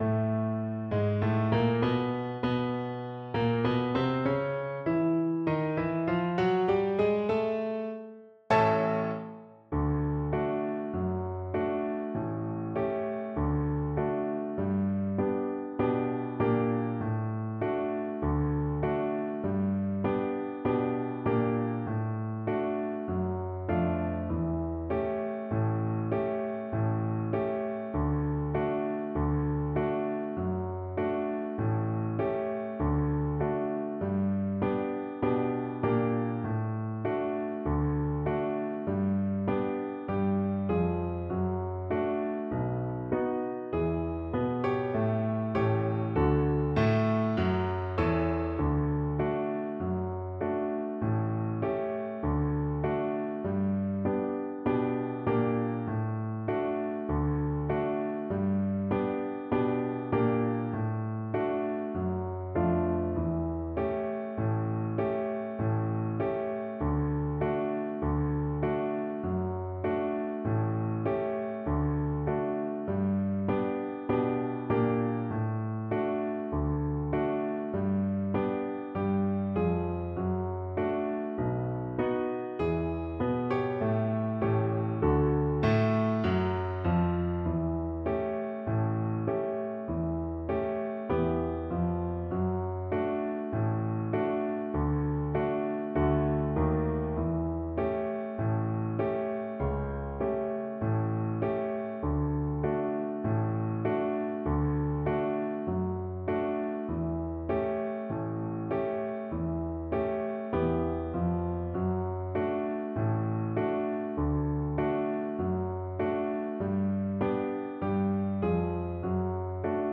Tempo di Marcia = 76 Tempo di Marcia
2/4 (View more 2/4 Music)
Jazz (View more Jazz Violin Music)
Ragtime Music for Violin